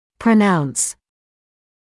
[prə’nauns][прэ’наунс]произносить; заявлять